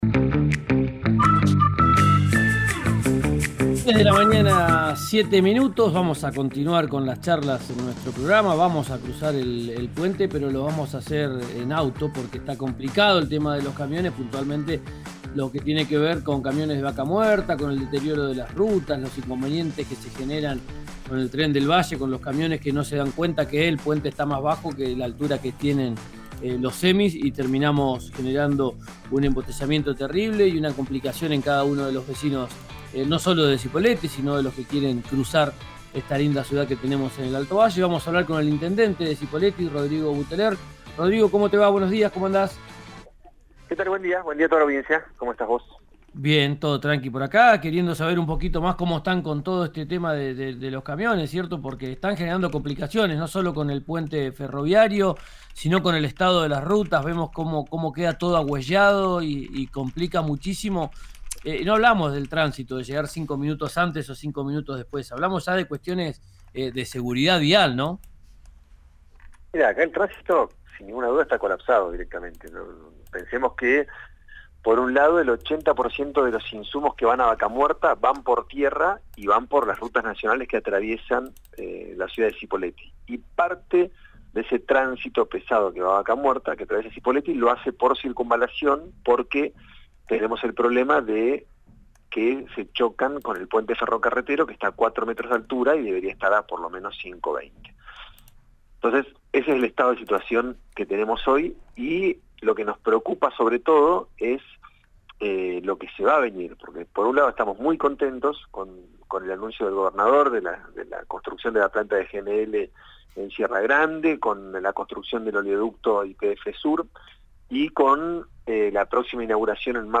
Escuchá a Rodrigo Buteler en RÍO NEGRO RADIO: